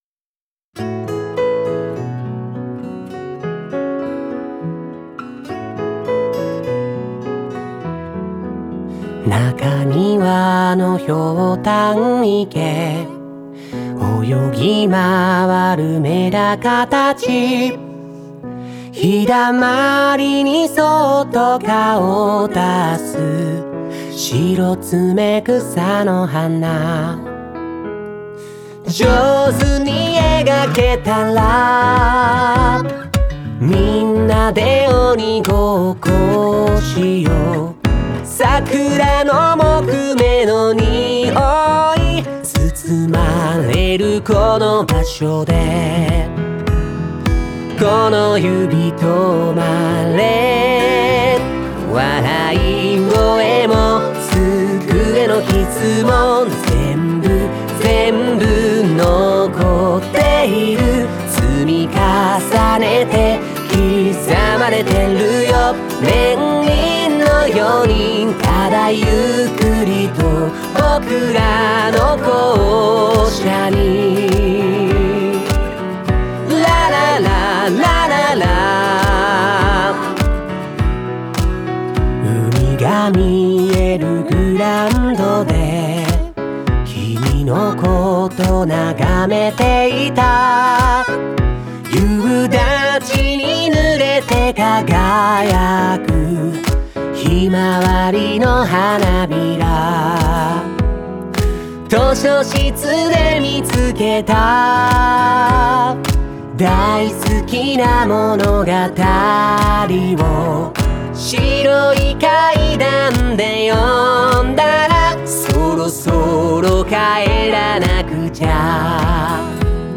創立150周年全校児童合唱